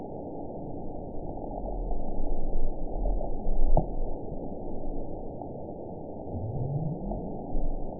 event 910626 date 01/23/22 time 18:14:23 GMT (3 years, 3 months ago) score 8.97 location TSS-AB06 detected by nrw target species NRW annotations +NRW Spectrogram: Frequency (kHz) vs. Time (s) audio not available .wav